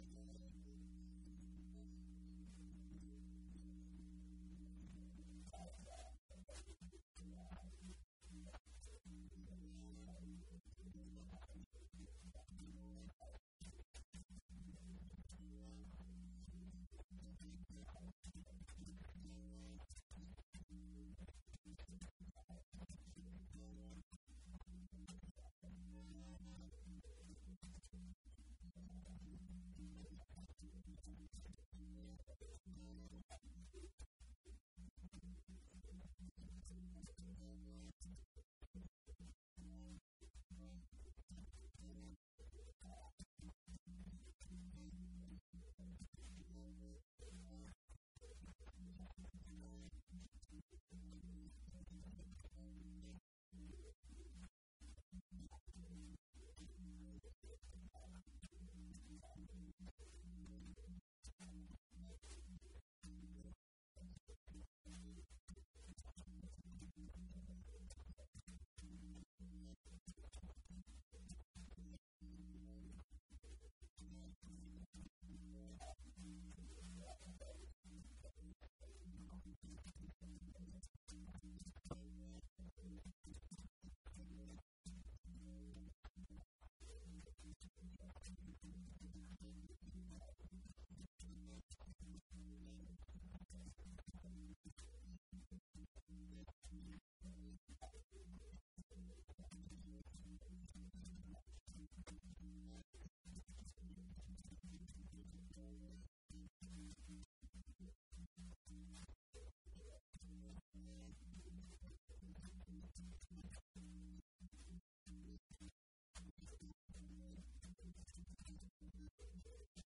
Event details for Paul Gl. Allen School distinquished lectures, colloquium, dissertations defense and more.